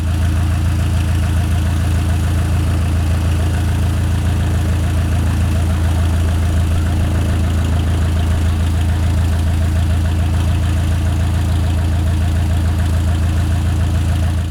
propeller-plane-idle-01.wav